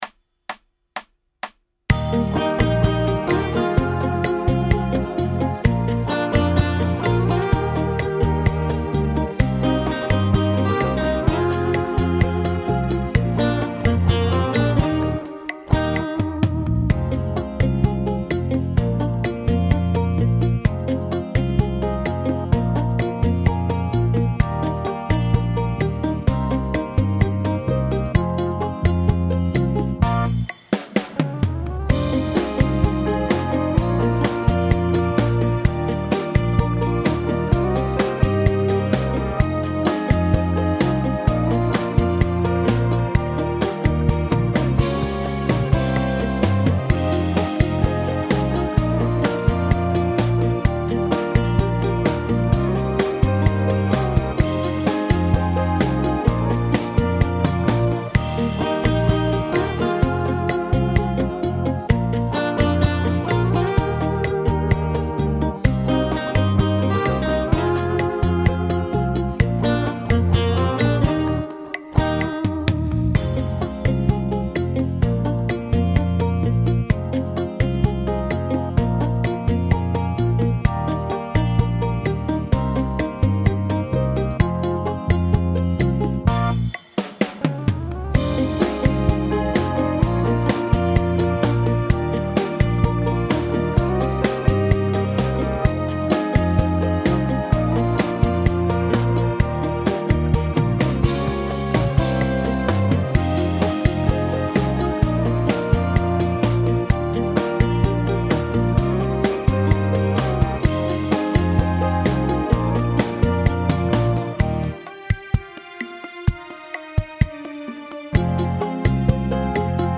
Genere: Bachata
Scarica la Base Mp3 (3,20 MB)